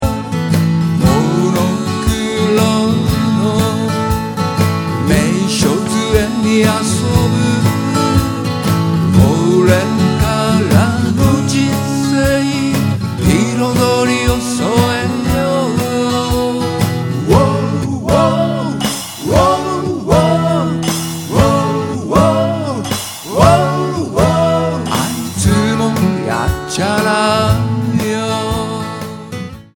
Percussion / Cajon / Cho.
Vo. / A.Guitar
A.Guitar / E.Guitar / Cho.